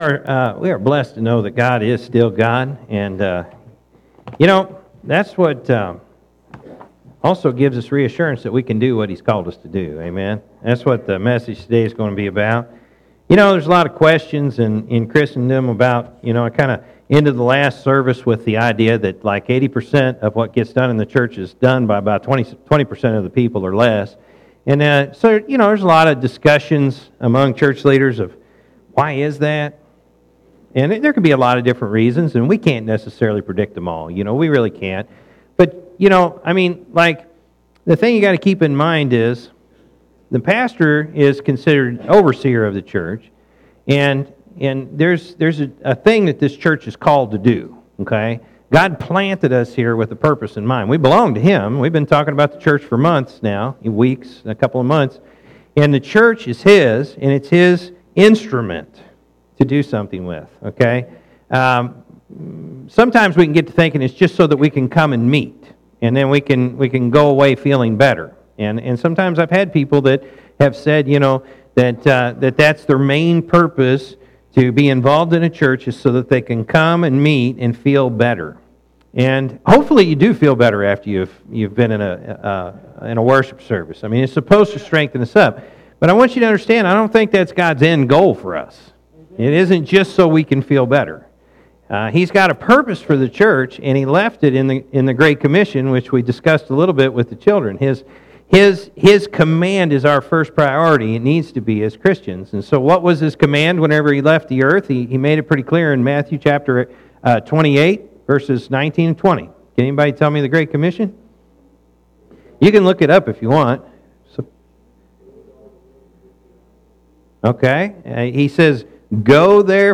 Oct-8-2017-morning-service.mp3